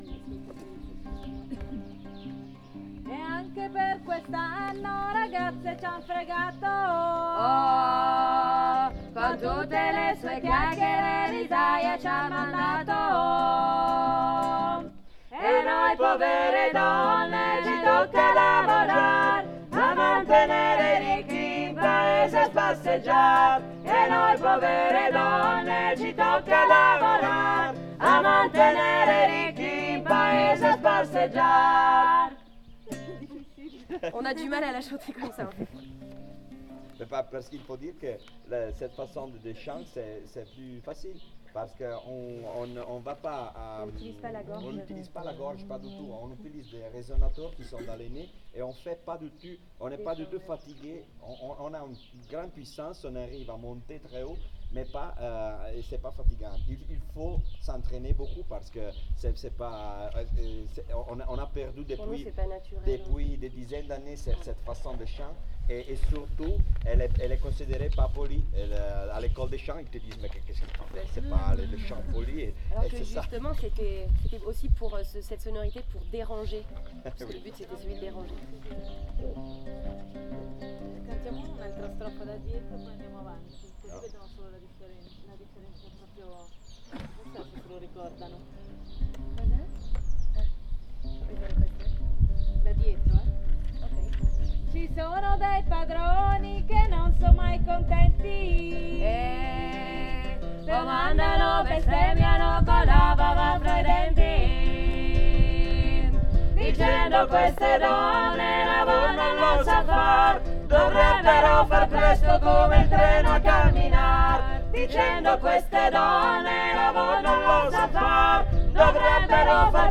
E_anche_per_quest_anno_TRIO.mp3